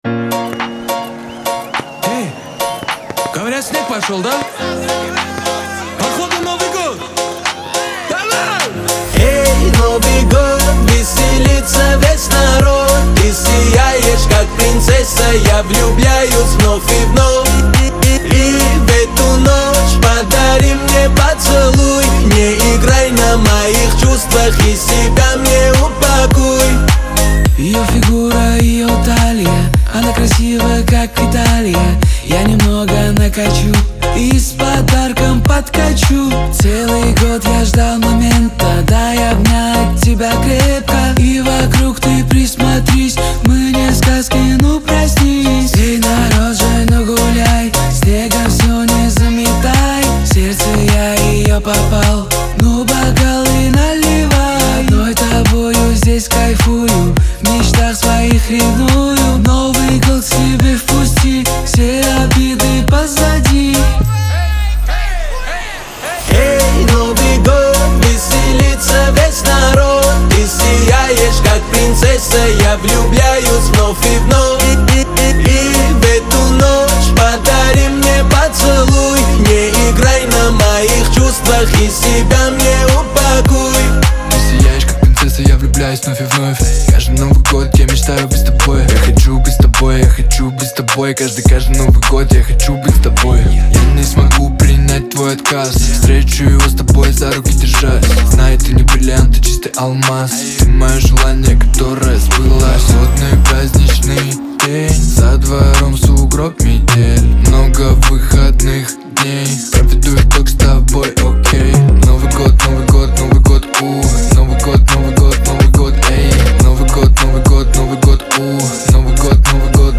pop
диско , дуэт
эстрада